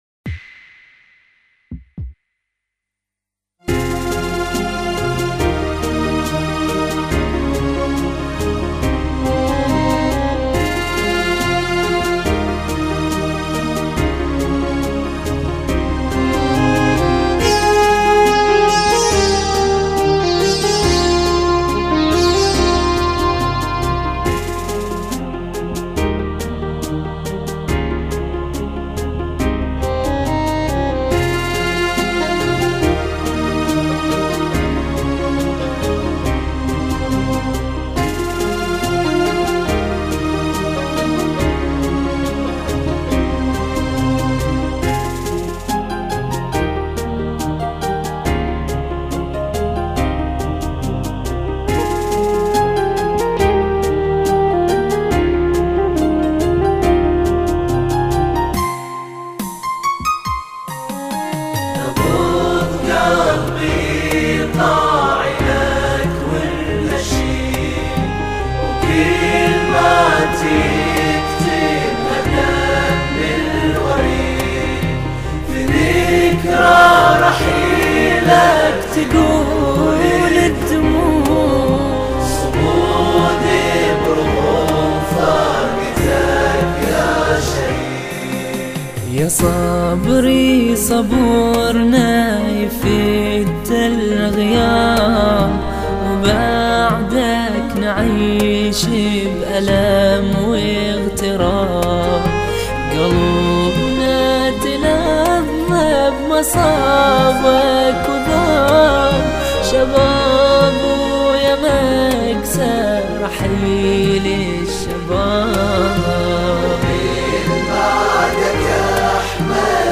أناشيد بحرينية